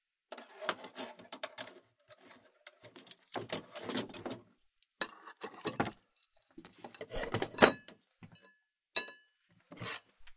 电动螺丝刀 " 电动螺丝刀2
描述：电动螺丝刀的录音。使用Rode NT2000记录。这个声音是循环准备好的。
Tag: 螺丝刀 电动工具